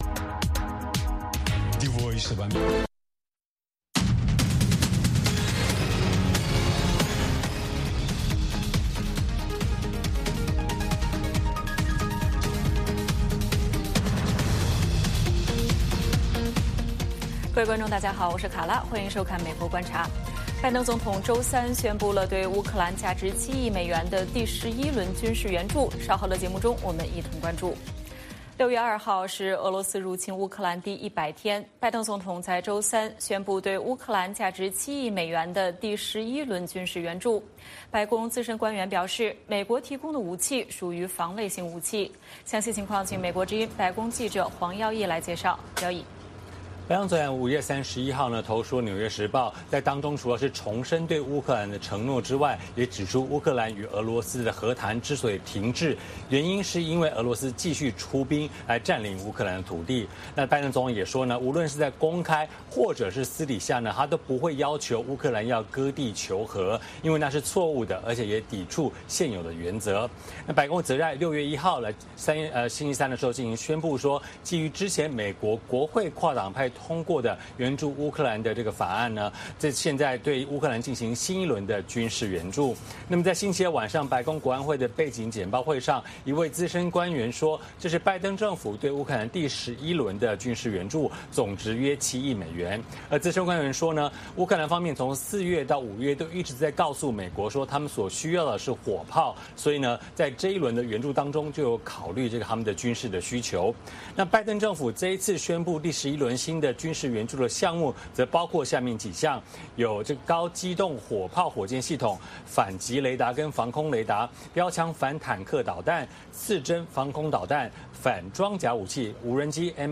前美国国防部长埃斯珀在接受美国之音专访时说，拜登在日本所说的“美国会军事保卫台湾”预示美国政府在台湾问题上的“战略模糊”政策或将终结。